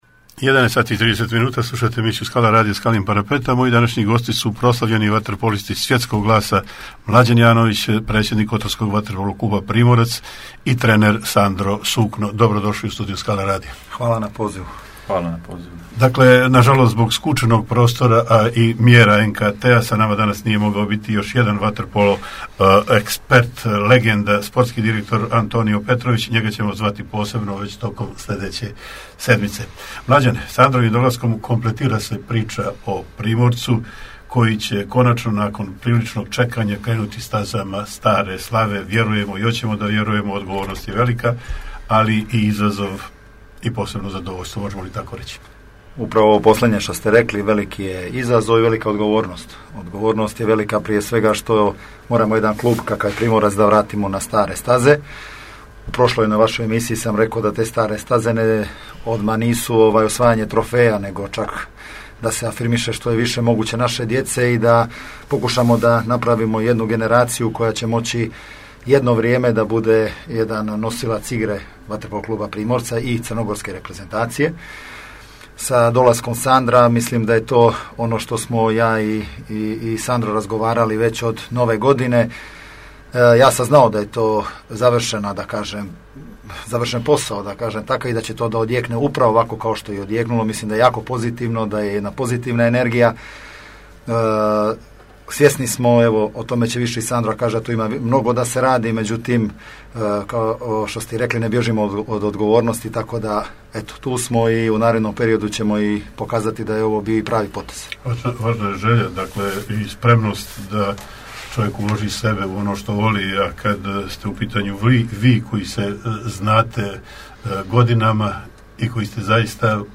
Gosti emisije predsjednik i trener Vaterpolo kluba “Primorac” Kotor, Mlađan Janović i Sandro Sukno